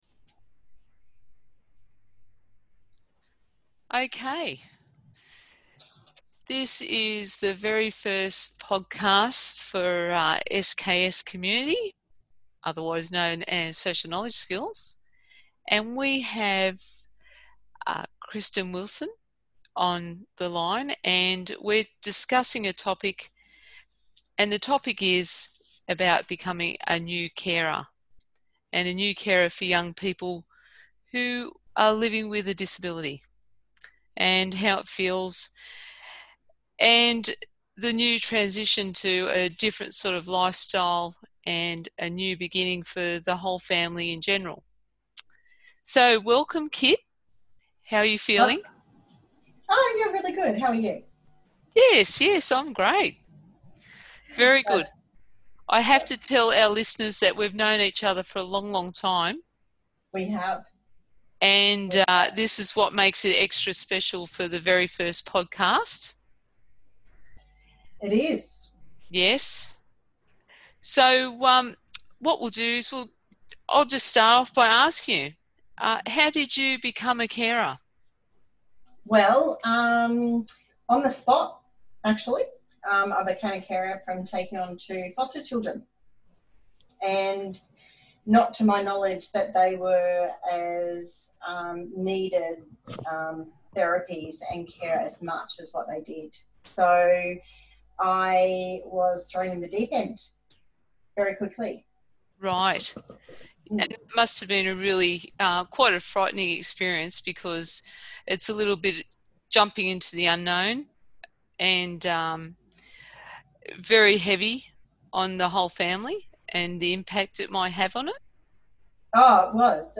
A frank, honest and raw heartfelt conversation about life after caring.